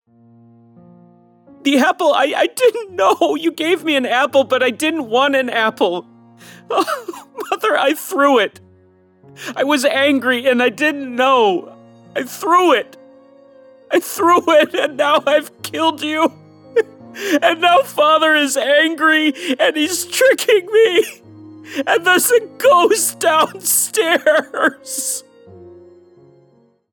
Drama 2
English - Midwestern U.S. English